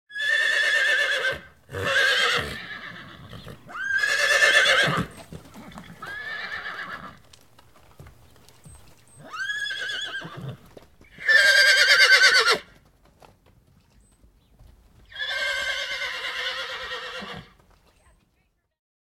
Звуки жеребца